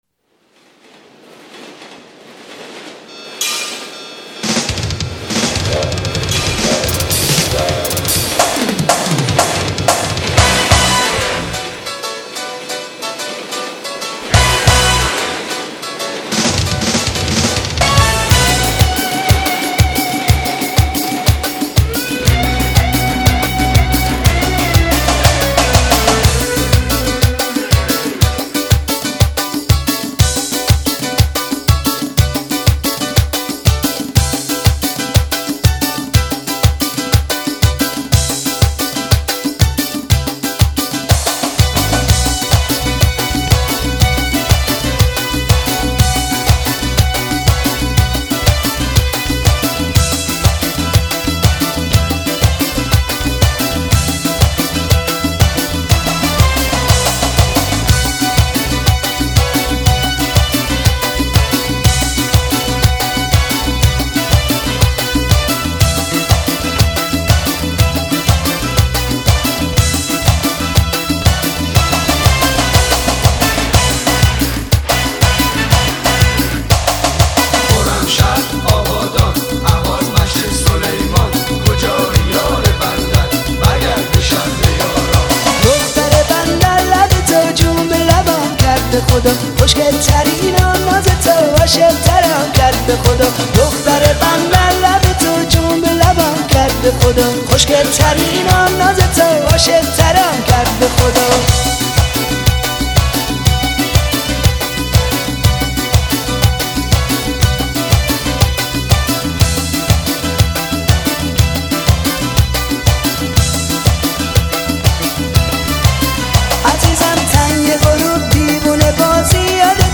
ژانر: پاپ
اهنگ شاد رقصیدنی ریمیکس